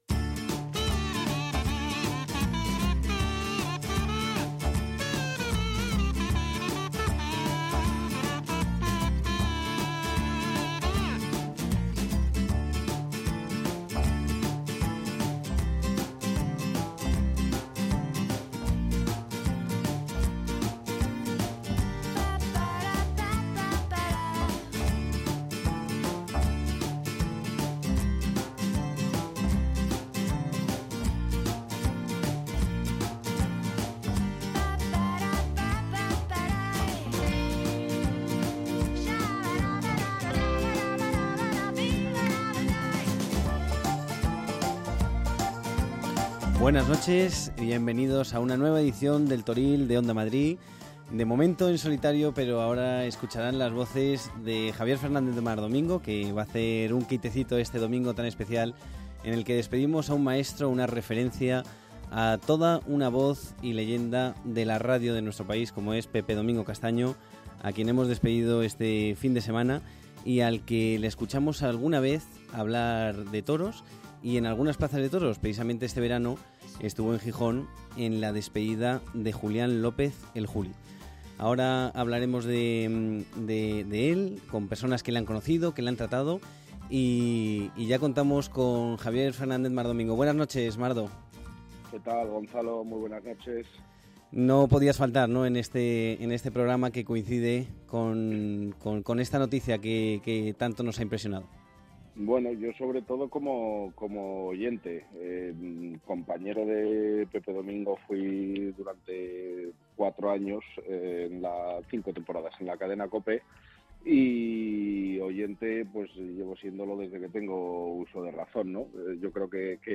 Habrá información pura y dura y entrevistas con los principales protagonistas de la semana.